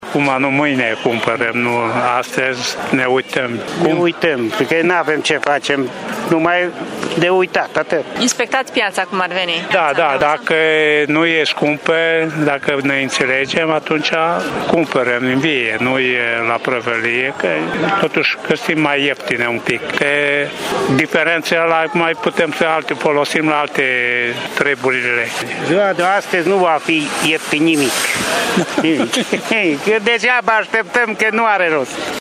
Mai mulți târgumureșeni au venit, astăzi, în Piața Cuza Vodă doar pentru a testa piața. Oamenii nu se așteaptă la scăderi semnificative de preț la nici un produs, mai ales înainte de Paști: